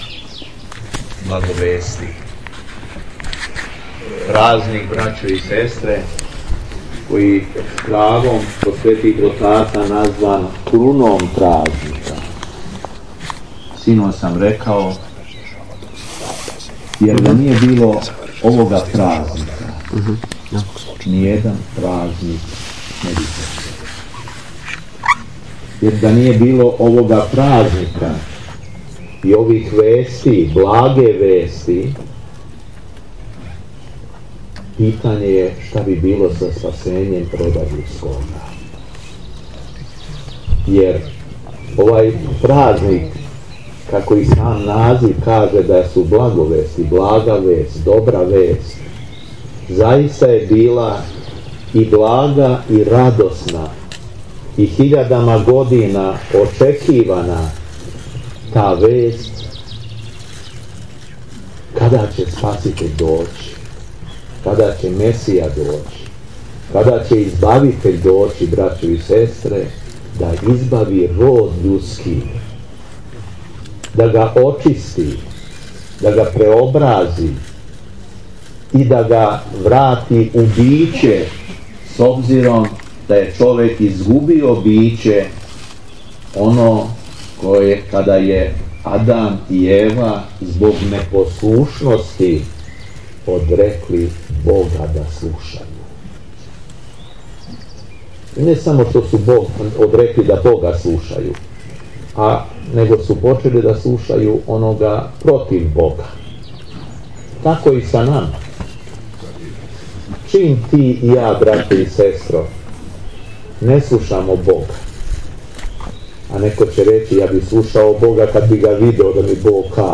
Беседа Епископа шумадијског Г. Јована
Након прочитаног одломка из Јеванђеља, владика Јован поучио је у беседи сабрани народ.